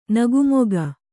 ♪ nagu moga